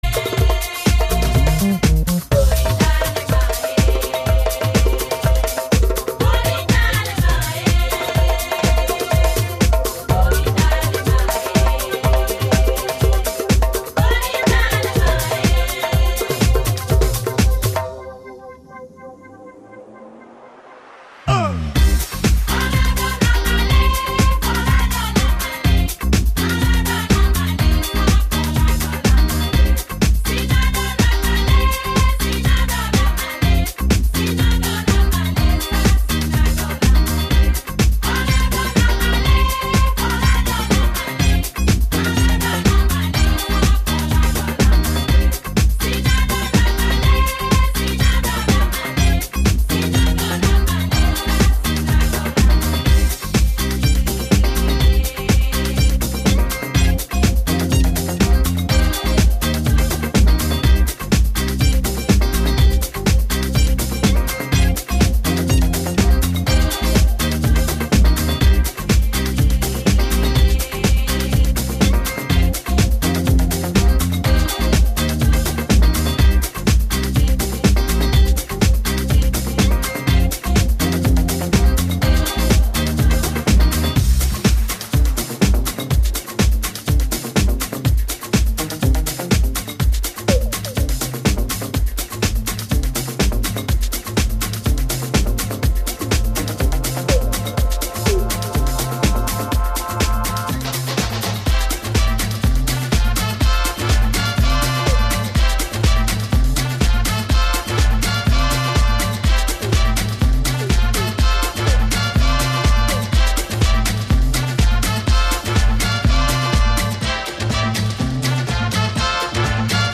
Male Ibiza DJ